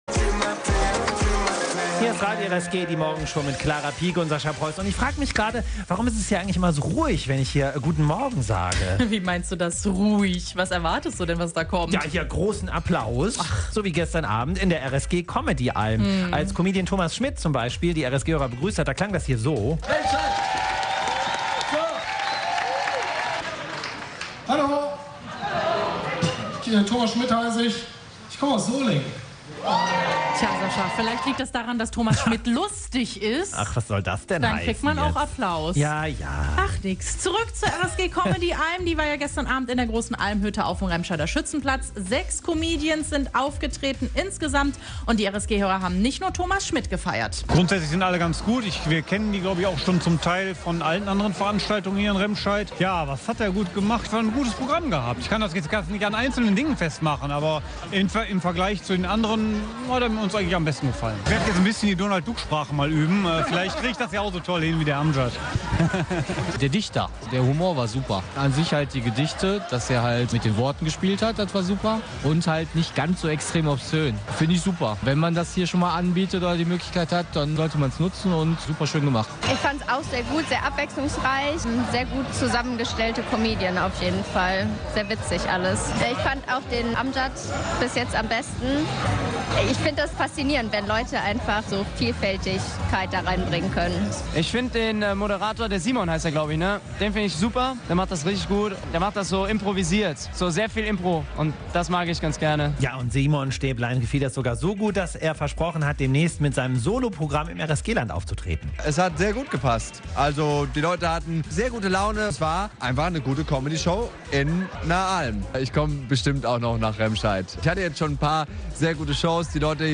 Comedy im Sixpack.
Die Remscheider Alm, das Eventzelt auf dem Schützenplatz, wurde am Dienstag zur Comedy-Alm. Gleich sechs Comedians gaben sich in der großen Holzhütte die Klinke in die Hand und begeisterten die Besucher.